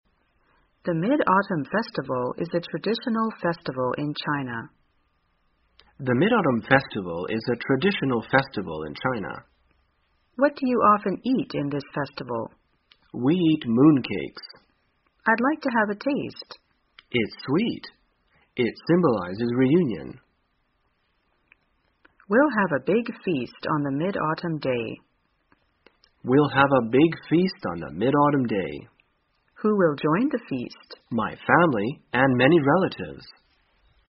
在线英语听力室生活口语天天说 第258期:怎样过中秋节的听力文件下载,《生活口语天天说》栏目将日常生活中最常用到的口语句型进行收集和重点讲解。真人发音配字幕帮助英语爱好者们练习听力并进行口语跟读。